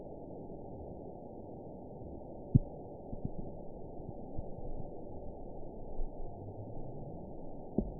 event 919785 date 01/23/24 time 13:40:20 GMT (1 year, 3 months ago) score 5.90 location TSS-AB06 detected by nrw target species NRW annotations +NRW Spectrogram: Frequency (kHz) vs. Time (s) audio not available .wav